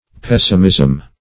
Pessimism \Pes"si*mism\, n. [L. pessimus worst, superl. of pejor